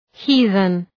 Προφορά
{‘hi:ðən}